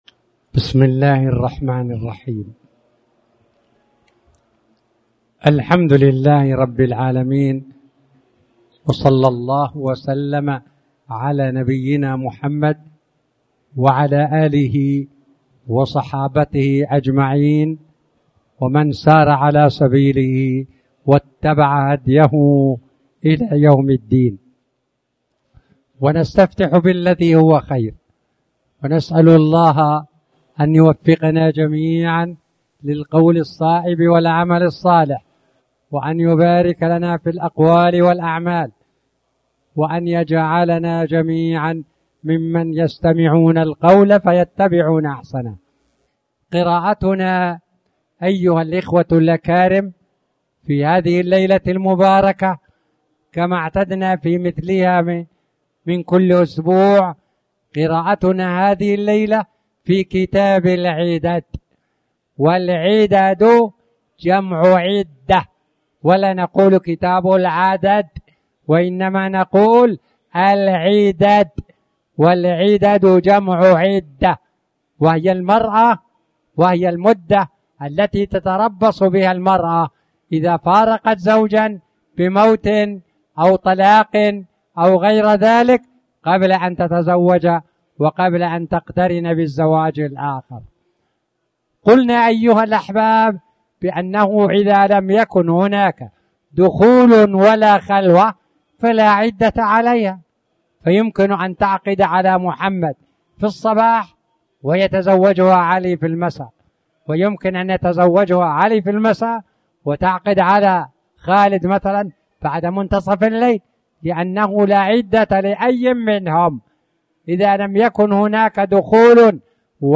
تاريخ النشر ٢٥ محرم ١٤٣٩ هـ المكان: المسجد الحرام الشيخ